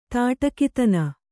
♪ tāṭakitana